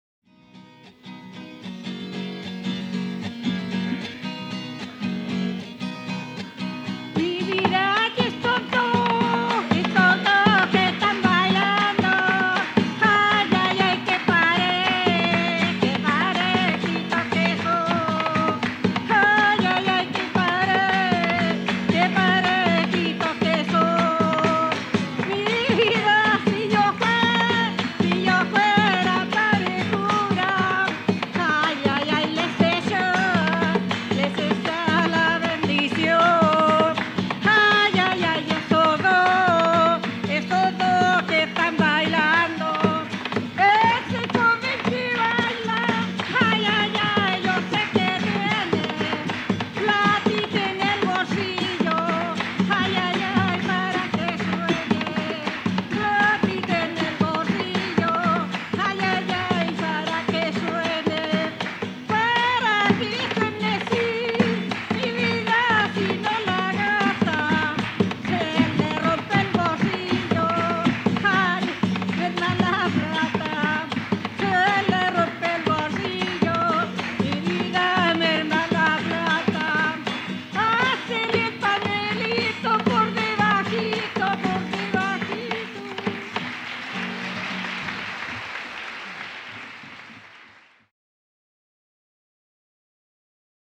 quien se acompaña de guitarra y cajón.
Música tradicional
Folklore
Cueca